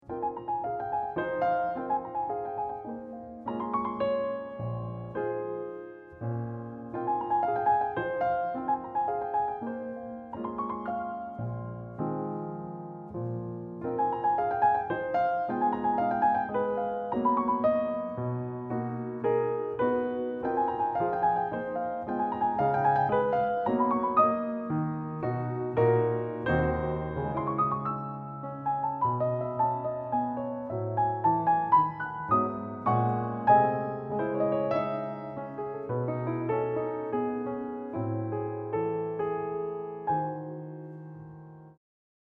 P i a n i s t   e n   m e e r . . .
maar ook door pop en jazz beïnvloed.
Live opgenomen in een mooie akoestische ruimte.